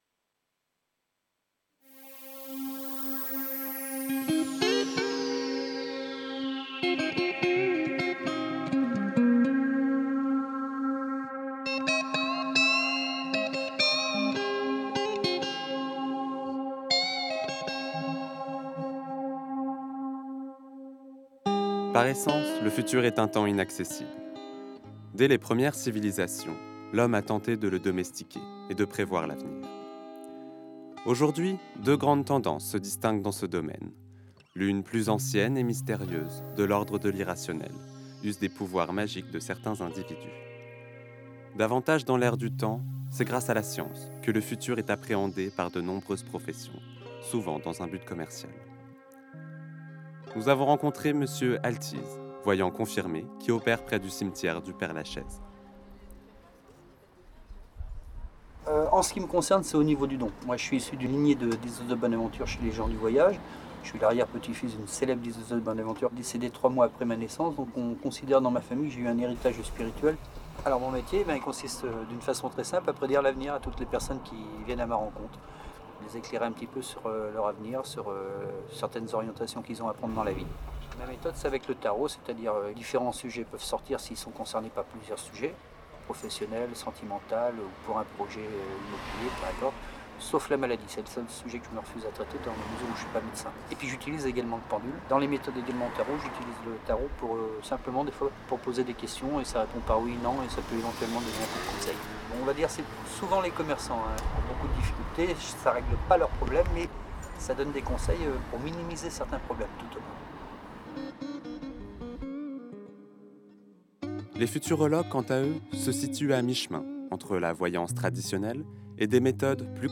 démo voix off